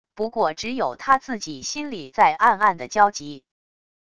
不过只有他自己心里在暗暗的焦急wav音频生成系统WAV Audio Player